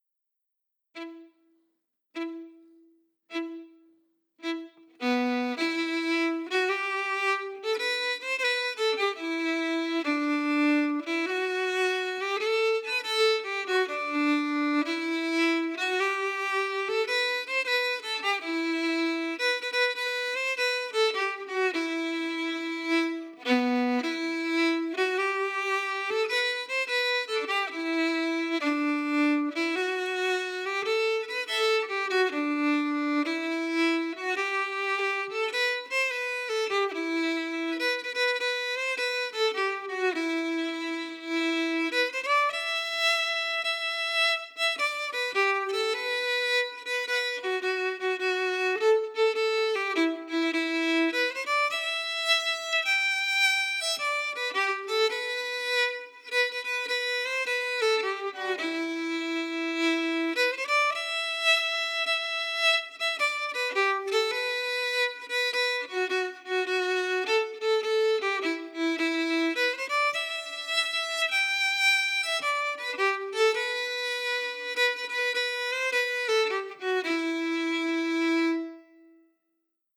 Key: Em
Form: Hornpipe
Played slowly for learning
Region: Ireland